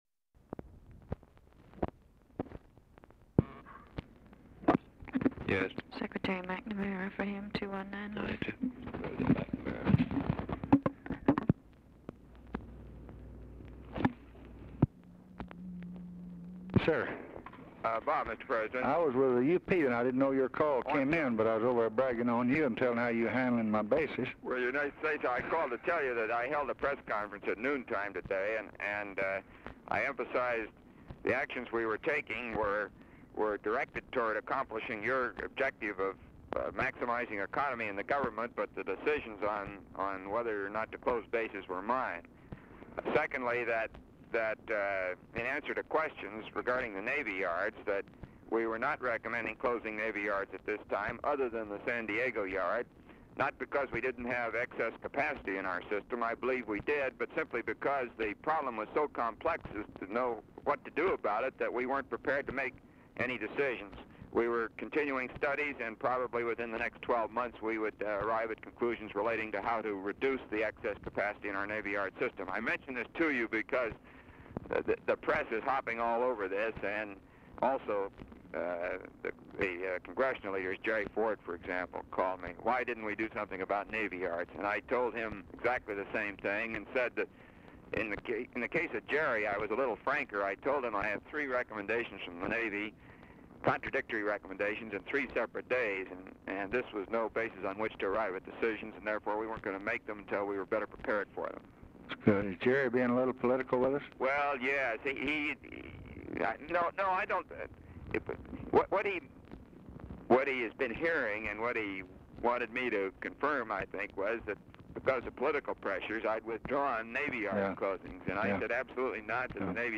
Telephone conversation # 453, sound recording, LBJ and ROBERT MCNAMARA, 12/12/1963, 3:14PM
Format Dictation belt
Location Of Speaker 1 Oval Office or unknown location